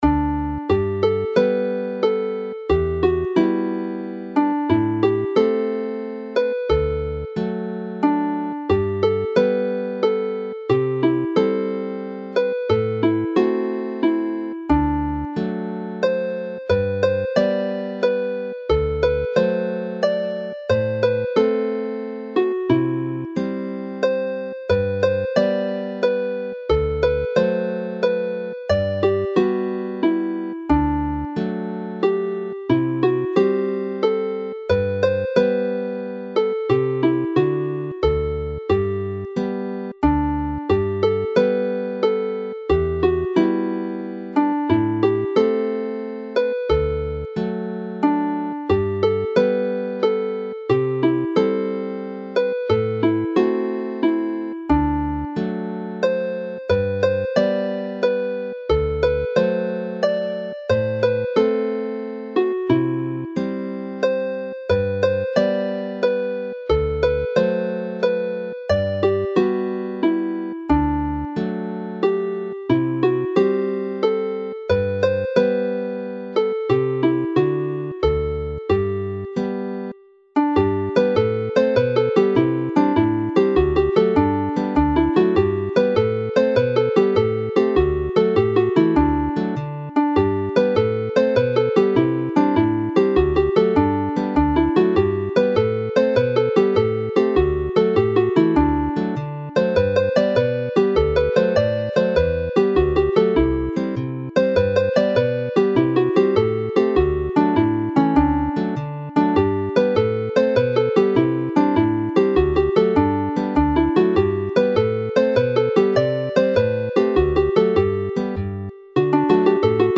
The reel Y Farchnad (the market) ends the set in a light and jolly way